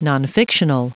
Prononciation du mot nonfictional en anglais (fichier audio)
Prononciation du mot : nonfictional